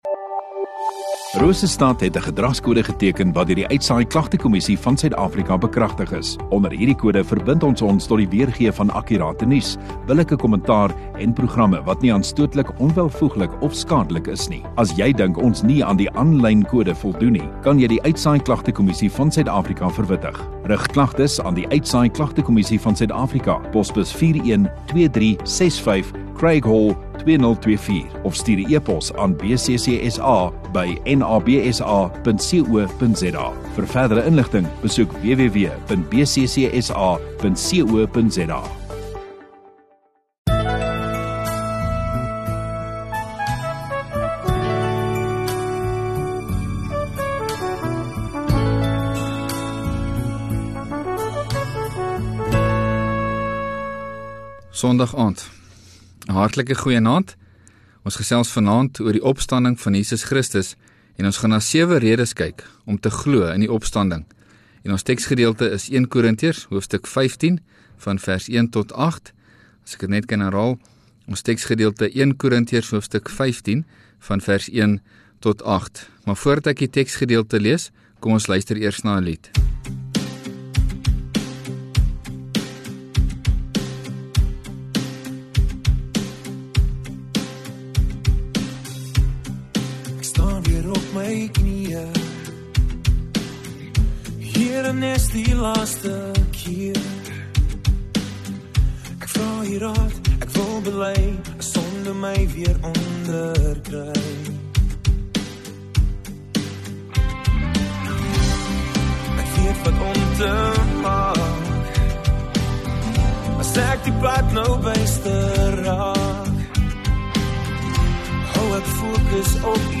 6 Apr Sondagaand Erediens